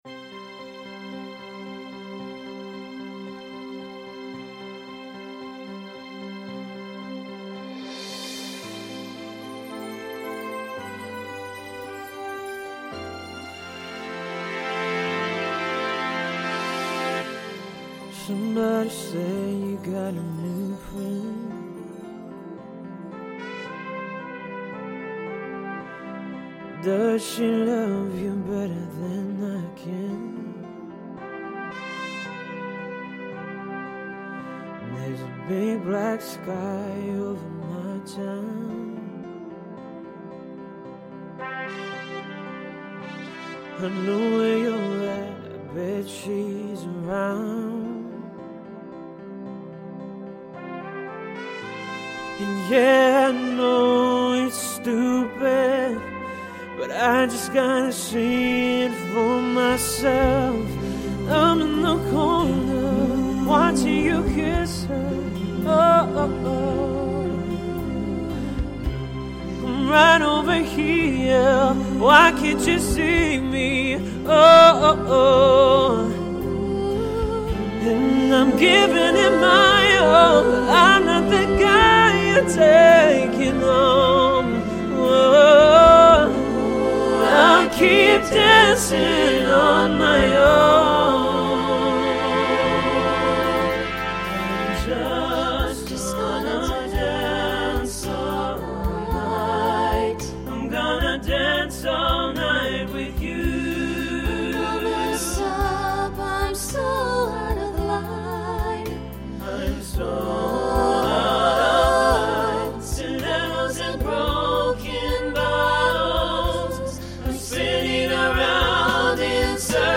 New TTB voicing for 2022.
Pop/Dance Decade 2010s Show Function Ballad